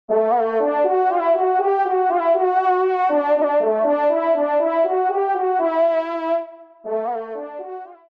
FANFARE
Extrait de l’audio « Ton de Vènerie »
Pupitre de Chant